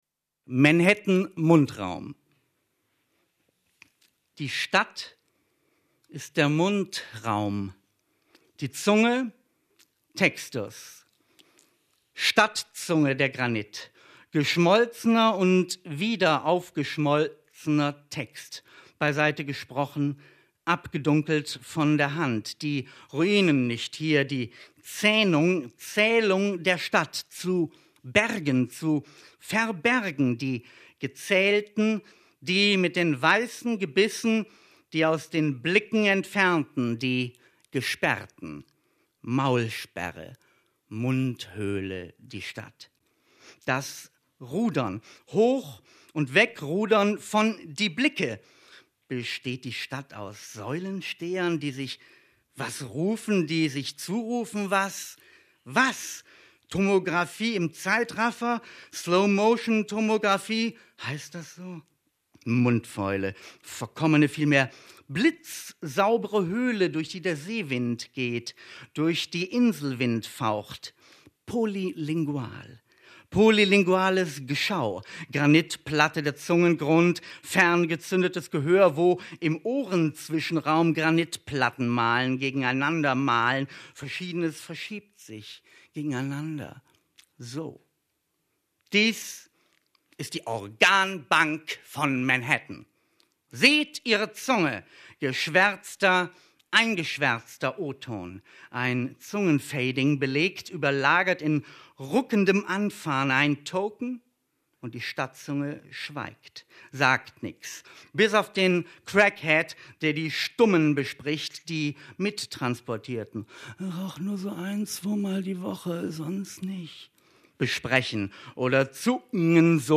Lesung von Thomas Kling in der literaturWERKstatt Berlin zur Sommernacht der Lyrik – Gedichte von heute